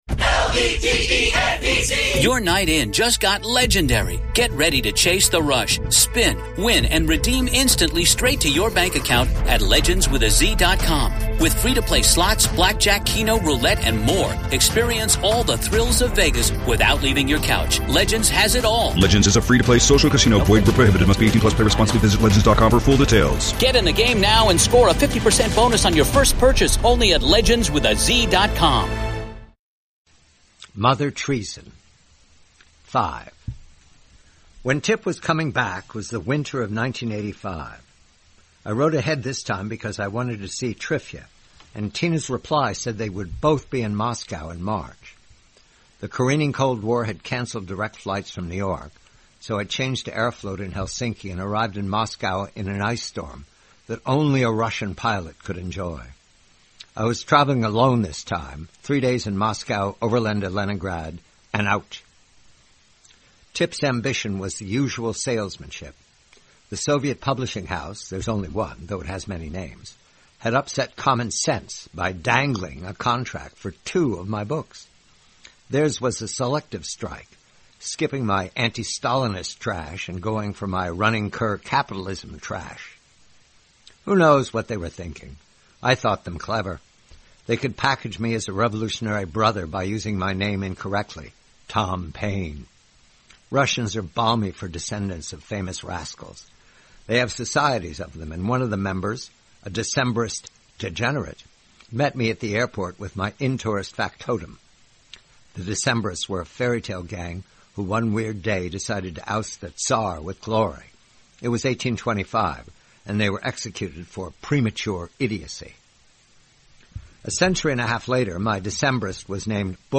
Read by John Batchelor.